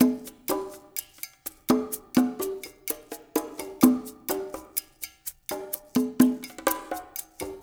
LAY PERC1 -L.wav